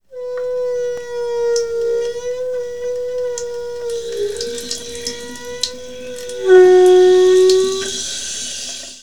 Singende Wasserleitung
Eine über 50 Jahre alte Waschamatur erzeucht beim langsamen Öffnen des Wasserhahn-Ventils Vibrationen (Frequenzen), die in unserem Hörbereich liegen. Der Druck, der durch das Wasser, welches durch die Ventilöffung strömt, herbeigerufen wird, erzeugt dieses Geräusch, abhängig von der Durchflussöffnung. Bei großer Öffnung ist kein (klarer) Ton mehr wahrzunehmen.